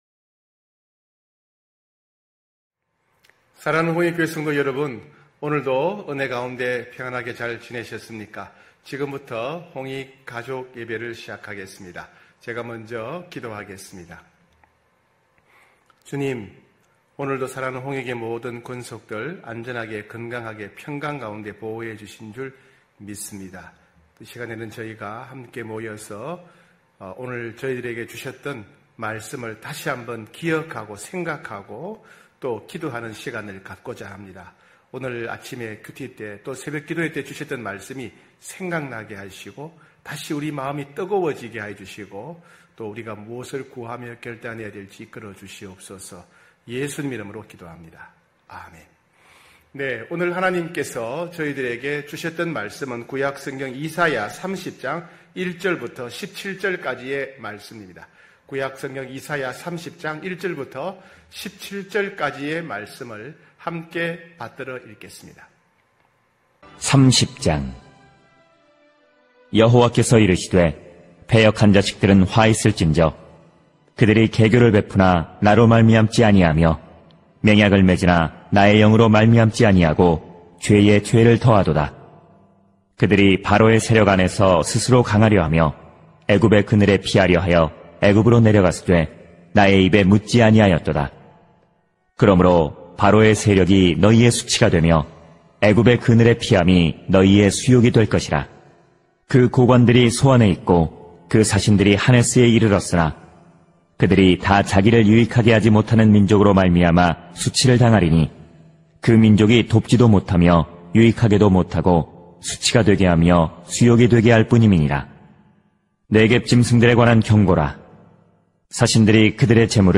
9시홍익가족예배(8월20일).mp3